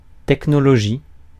Ääntäminen
Ääntäminen Tuntematon aksentti: IPA: /tɛk.nɔ.lɔ.ʒi/ Haettu sana löytyi näillä lähdekielillä: ranska Käännöksiä ei löytynyt valitulle kohdekielelle.